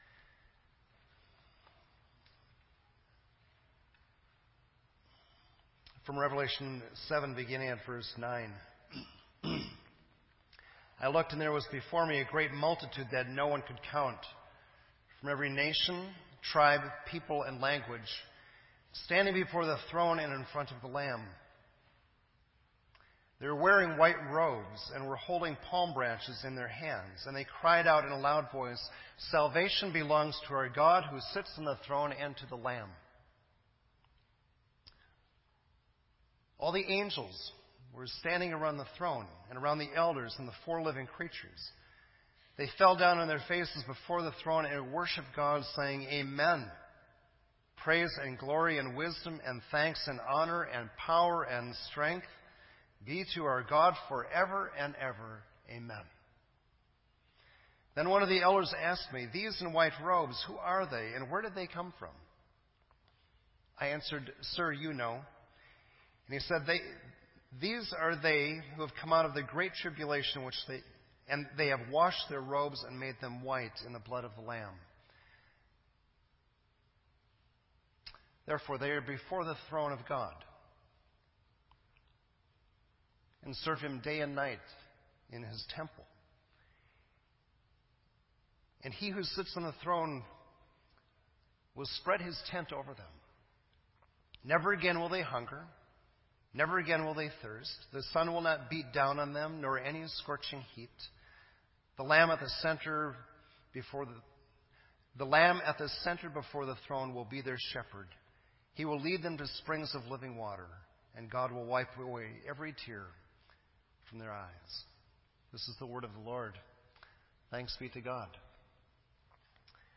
This entry was posted in Sermon Audio on November 21